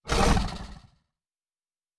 Large Creature 13 - Short 2.wav